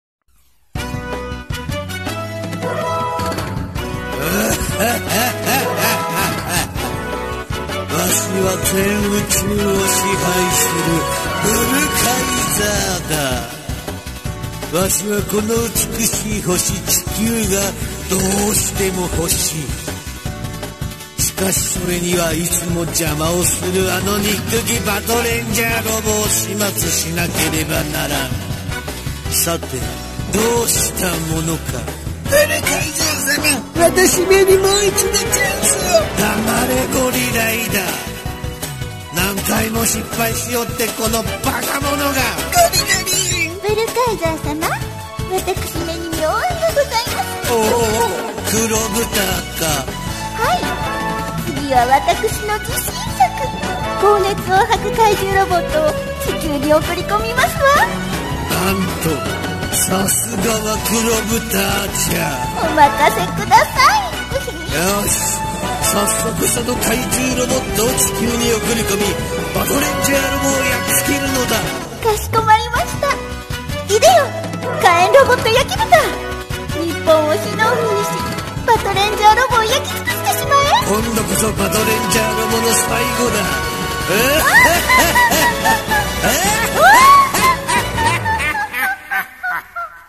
（声劇）悪者編・戦え！バトレンジャーロボ